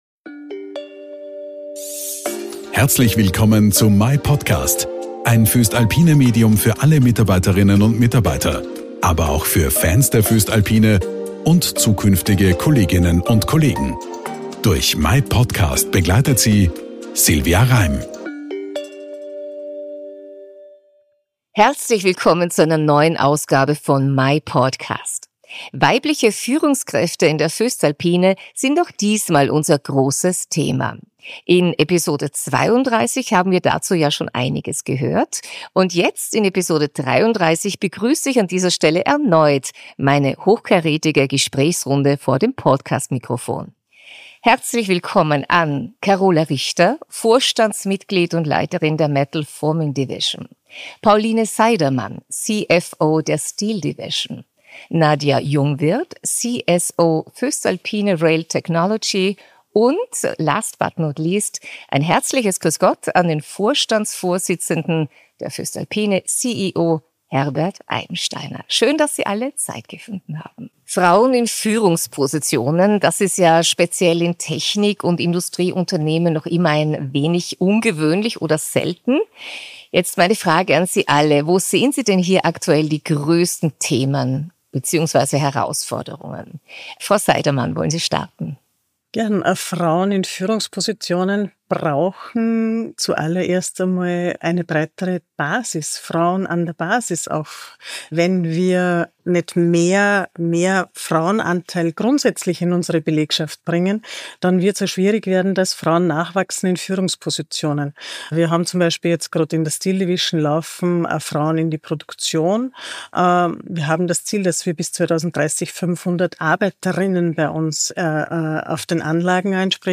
erneut eine hochkarätige Gesprächsrunde